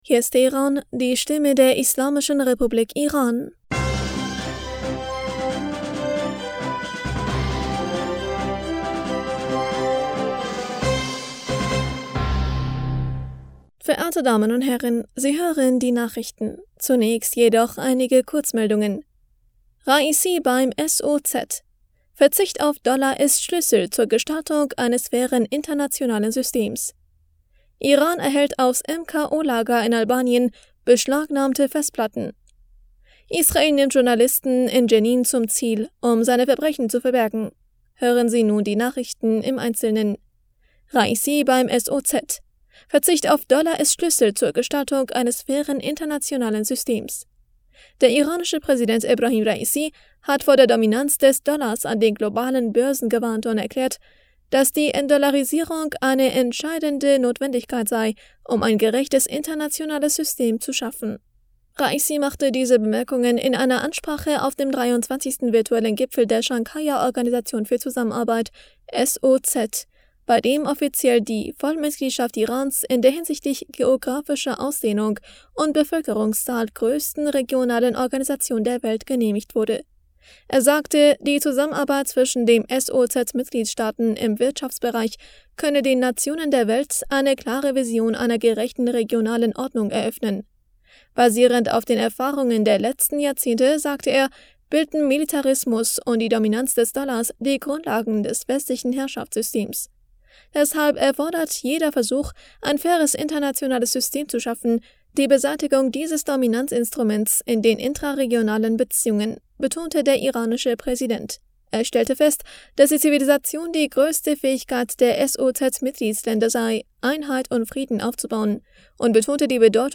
Die Nachrichten von Dienstag, dem 04. Juli 2023